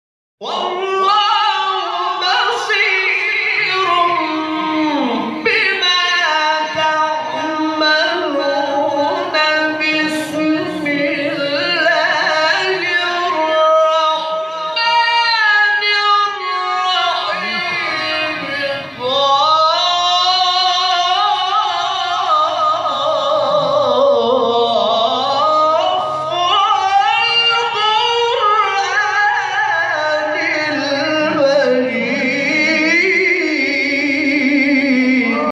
نغمات صوتی از قاریان ممتاز کشور